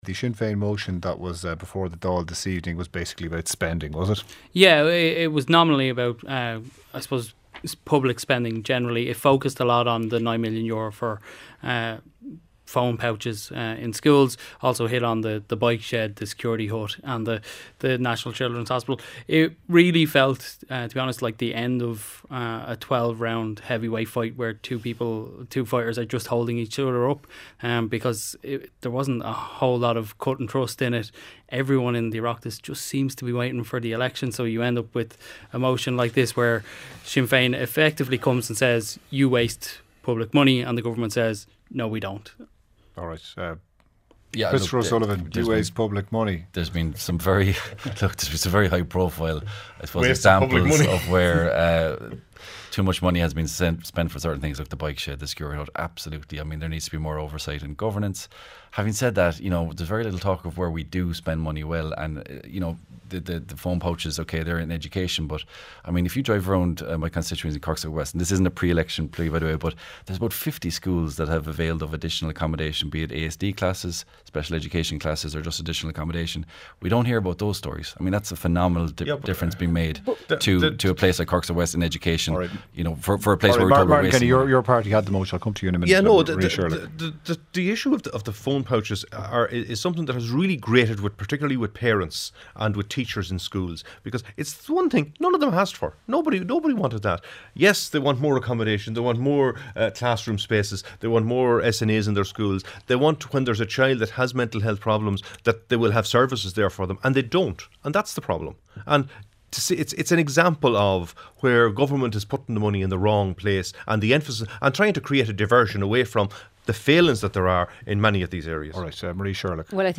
Weather Forecast - 09.10.2024